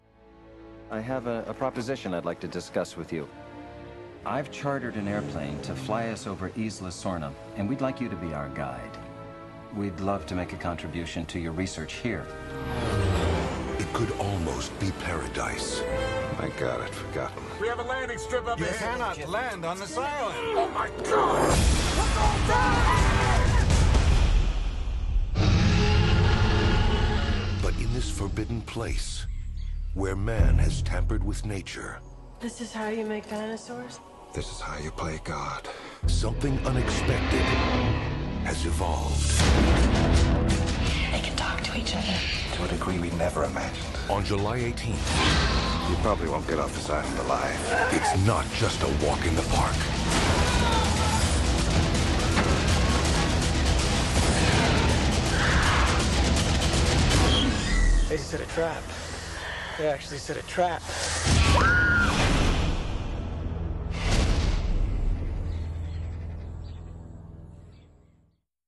Trailer Audio: Jurassic Park III (2001)
jurassic-park-iii-trailer.mp3